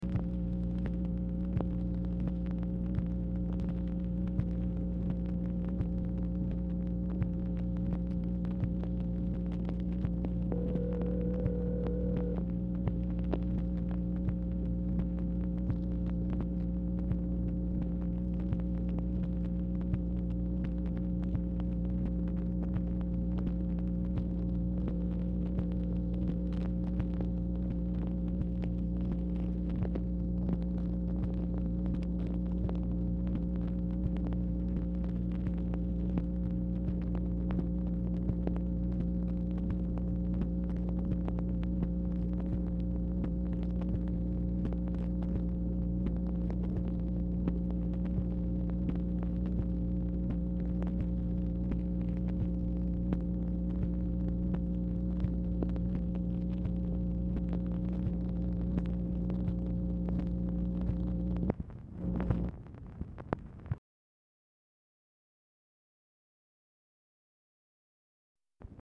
Telephone conversation # 10670, sound recording, MACHINE NOISE, 8/31/1966, time unknown | Discover LBJ
Telephone conversation
Format Dictation belt